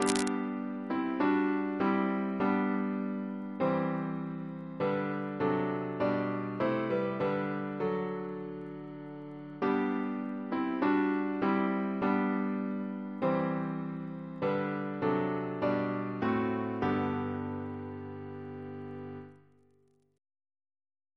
CCP: Chant sampler
Double chant in G Composer: Gilbert Heathcote (1765-1829) Reference psalters: ACB: 319; ACP: 291; PP/SNCB: 198